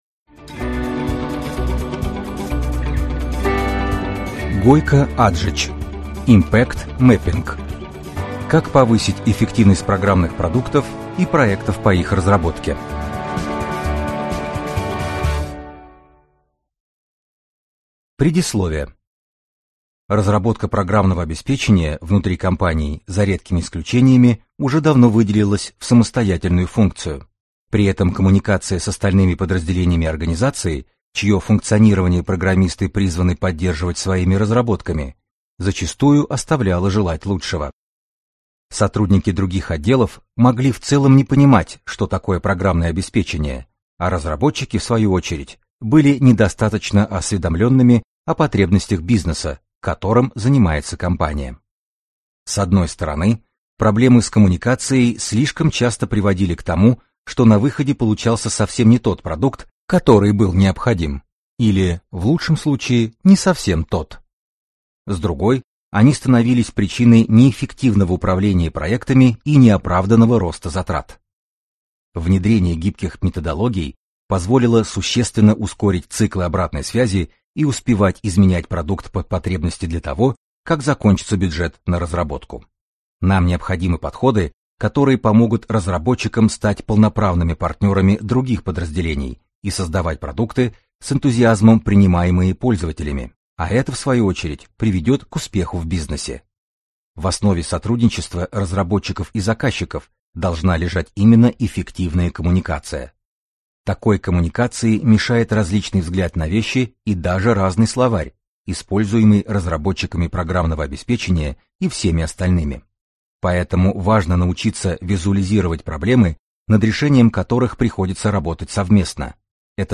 Аудиокнига Impact mapping: Как повысить эффективность программных продуктов и проектов по их разработке | Библиотека аудиокниг